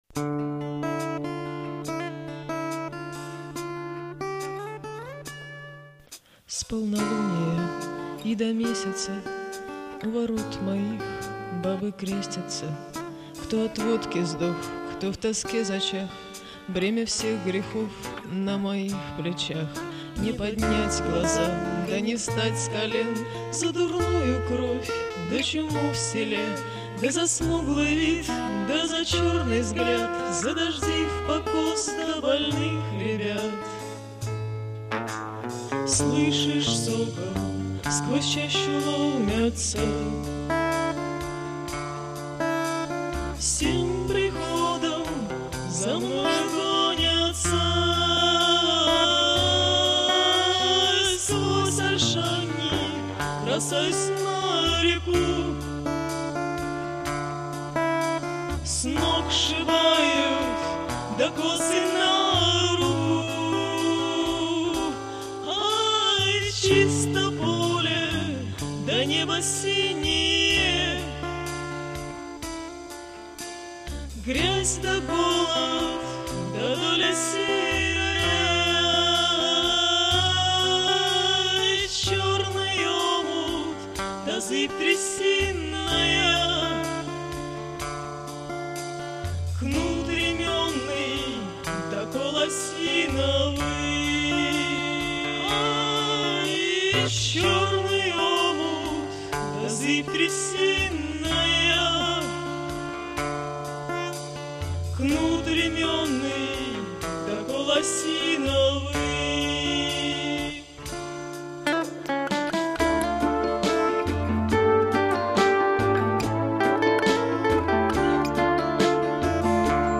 Жанр: авторская песня
Гитара